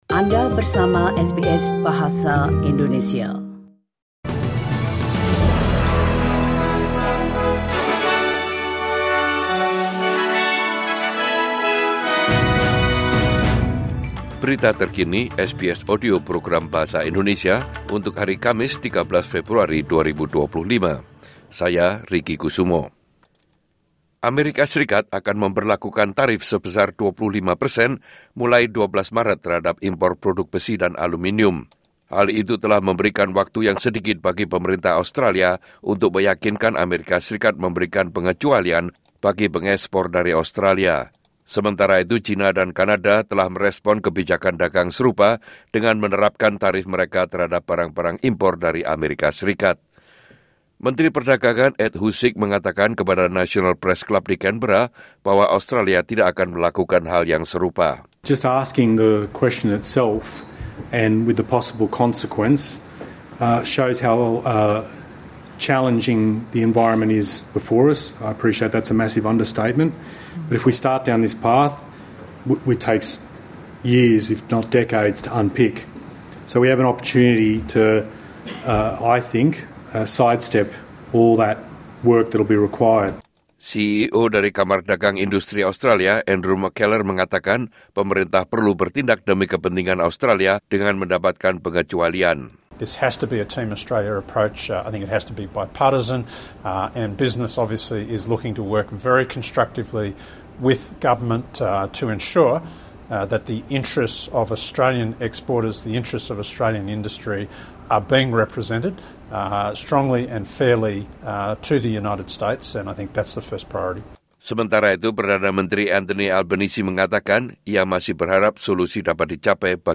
Warta Berita Radio SBS dalam Bahasa Indonesia Source: SBS